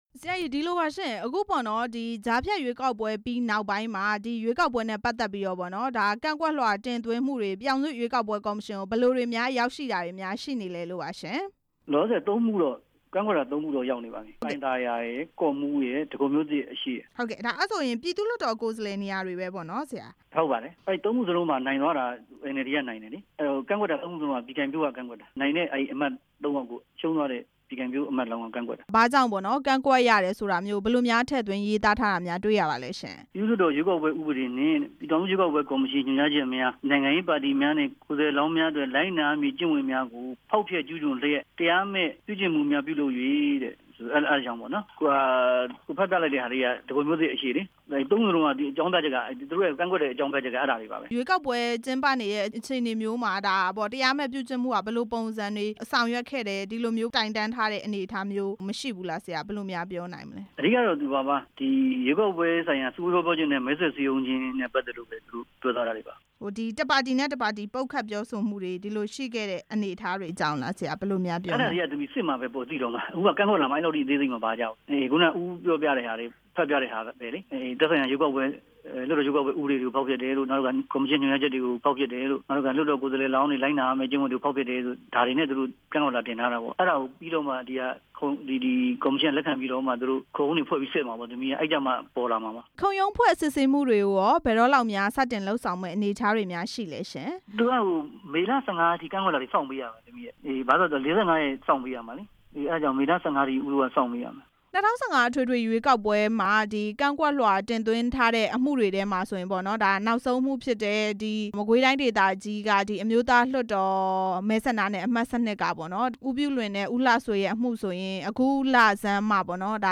NLD ကို ကြံ့ခိုင်ရေးပါတီ ကန့် ကွက်လွှာတင်သွင်းမှု မေးမြန်း ချက်